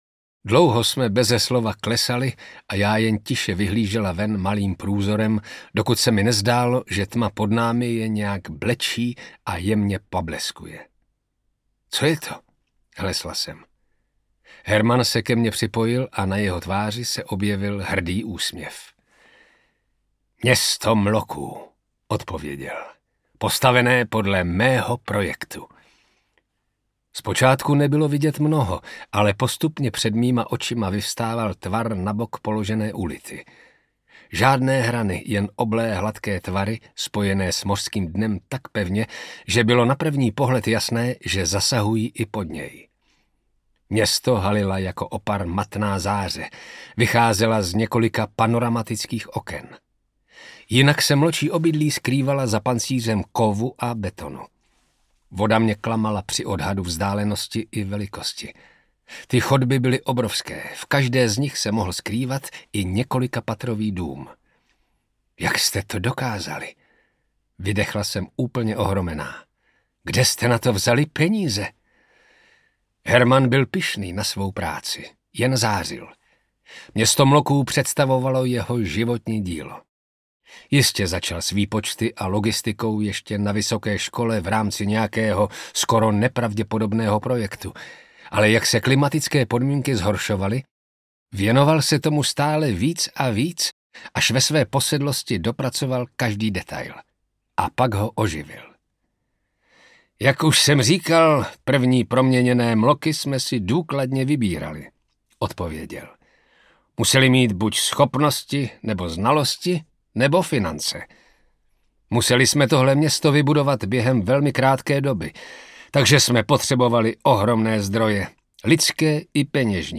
Věk mloků audiokniha
Vyrobilo studio Soundguru.